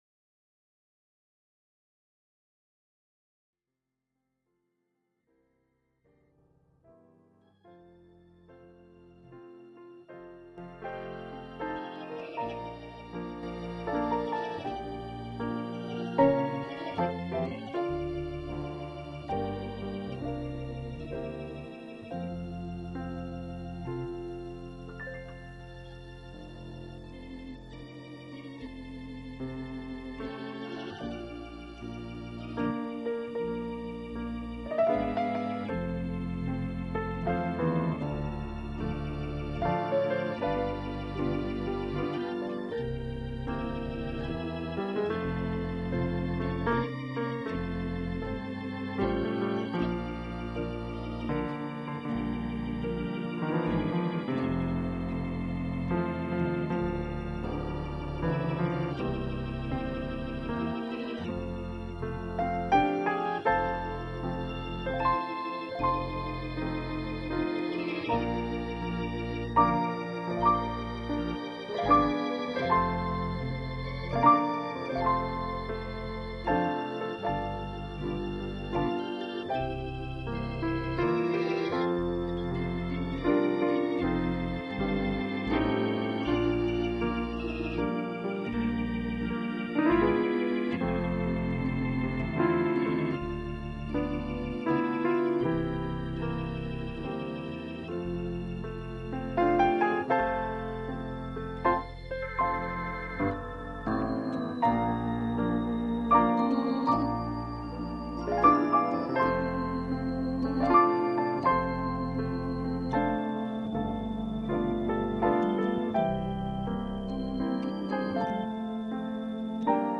Gospel Tape Ministry NZ - Resource Room - Sunday Sermons